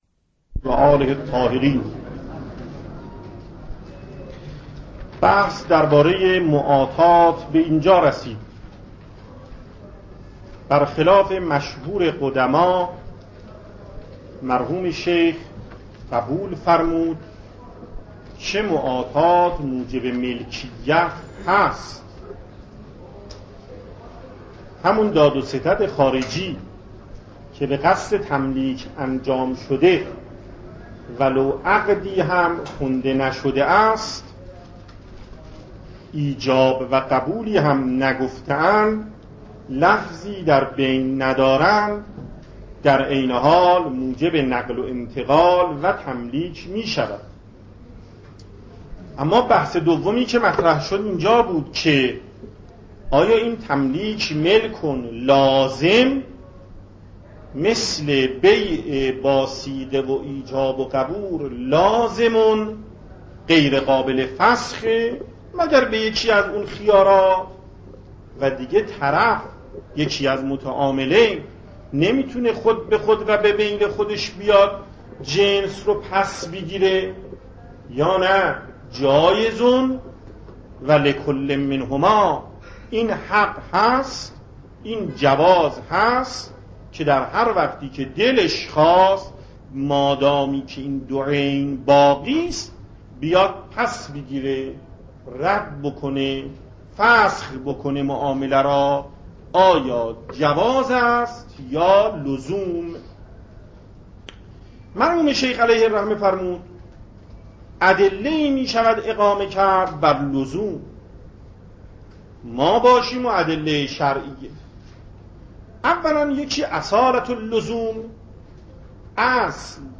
صوت و تقریر درس پخش صوت درس: متن تقریر درس: ↓↓↓ تقریری ثبت نشده است.